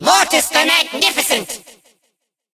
evil_mortis_lead_vo_01.ogg